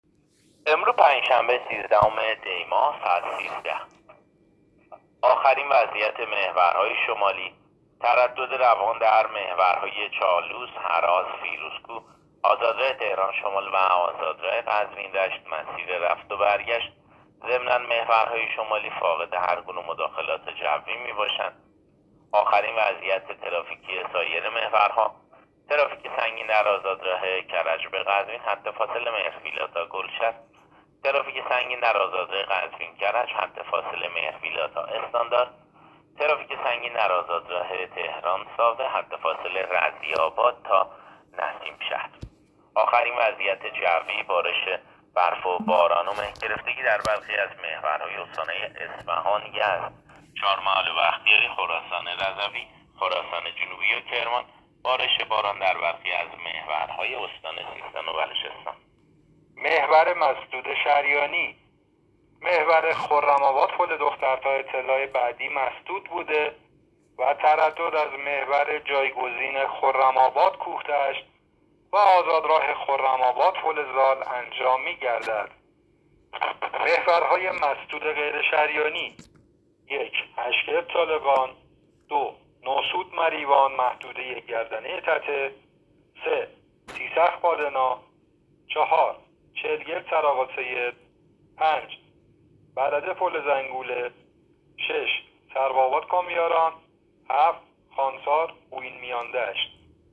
گزارش رادیو اینترنتی از آخرین وضعیت ترافیکی جاده‌ها تا ساعت ۱۳ سیزدهم دی؛